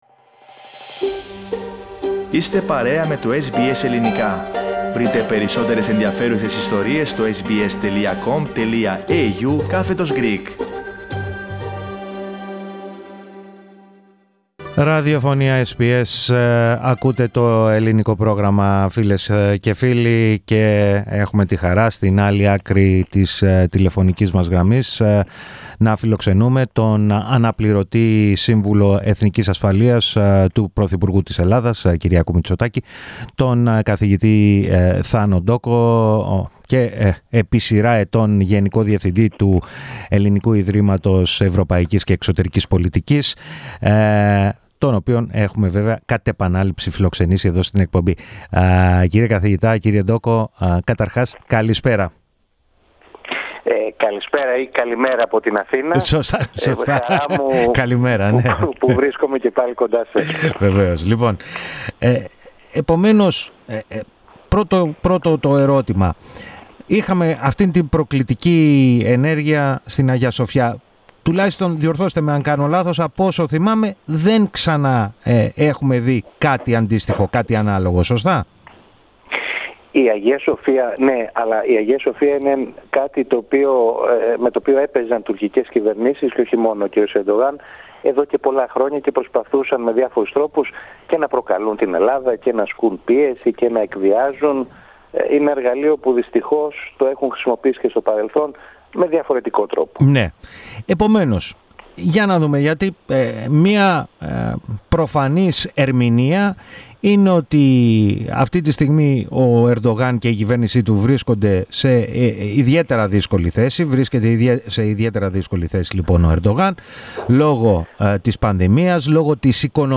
Στον απόηχo της νέα πρόκλησης Ερντογάν στην Αγιά Σοφιά, μίλησε στο Ελληνικό Πρόγραμμα της ραδιοφωνίας SBS, ο αναπληρωτής Σύμβουλος Εθνικής Ασφαλείας, του πρωθυπουργού Κ. Μητσοτάκη, Δρ. Θάνος Ντόκος.